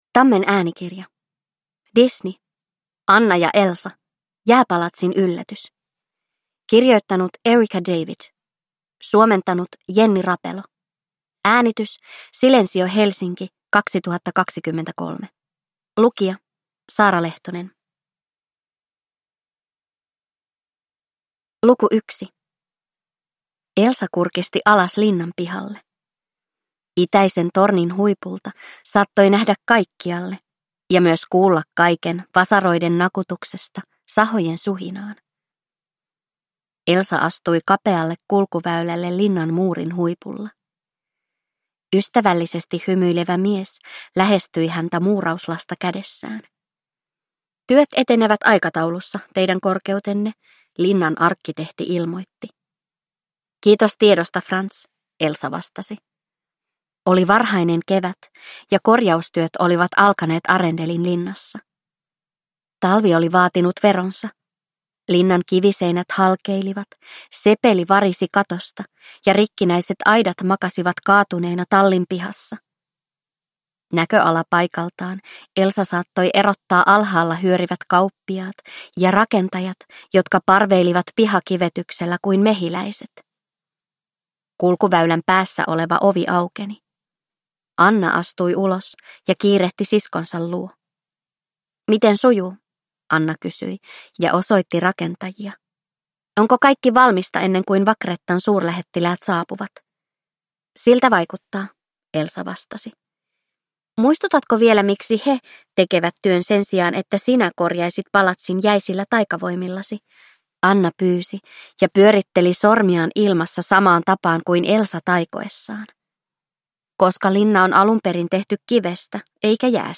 Frozen. Anna & Elsa. Jääpalatsin yllätys – Ljudbok – Laddas ner